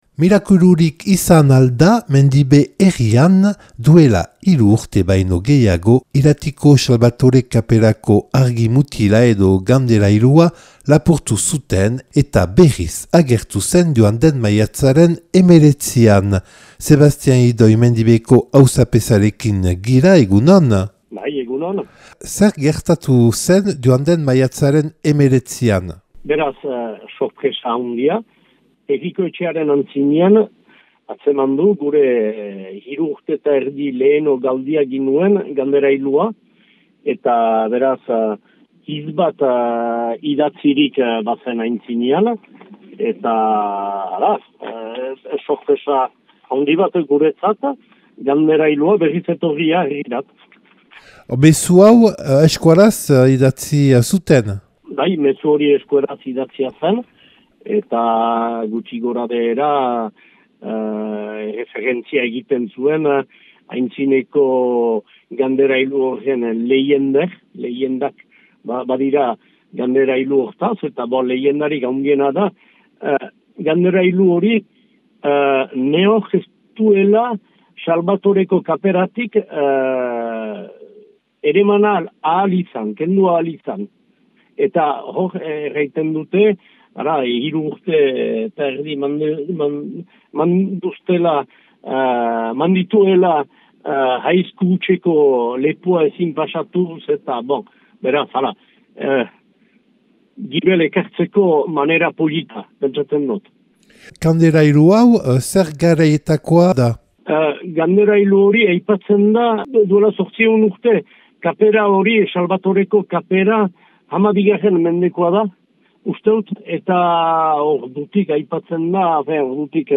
Duela hiru urte baino gehiago Iratiko Salbatore kaperako argimutila edo kanderailua lapurtu zuten eta berriz agertu zen joan den maiatzaren hemeretzian. Sébastien Ihidoy Mendibeko auzapezaren erantzunak.